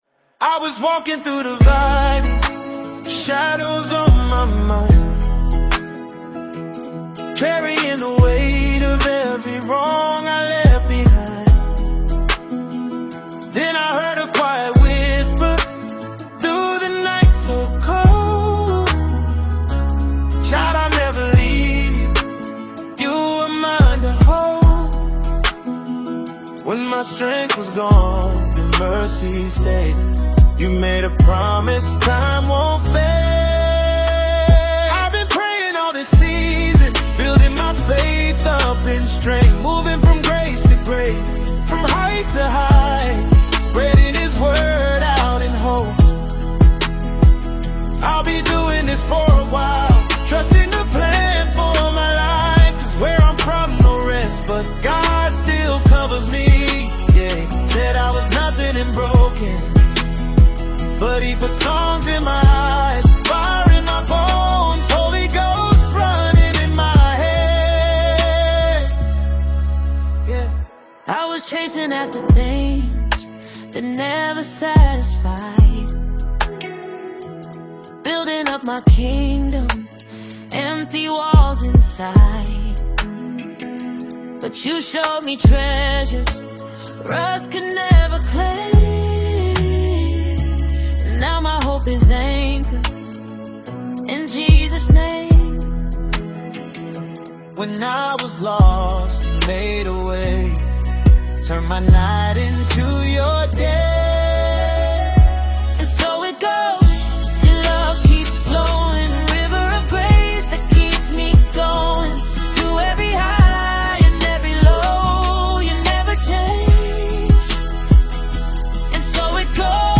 A Rising Voice in African Gospel Music